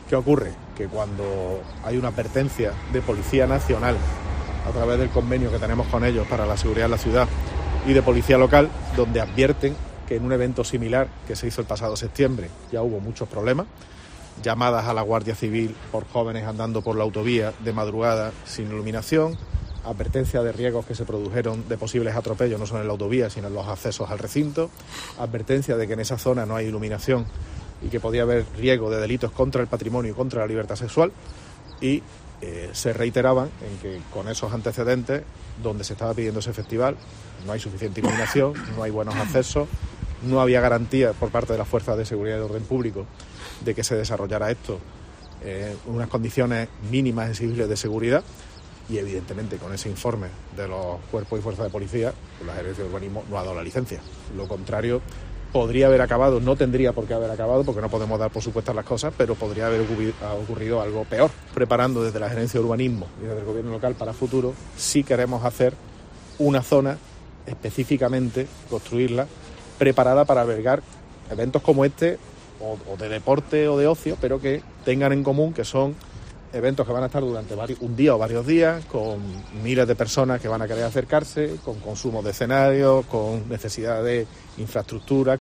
El alcalde de Córdoba, José María Bellido, explica los motivos de la suspensión del festival